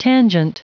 Prononciation du mot tangent en anglais (fichier audio)
Prononciation du mot : tangent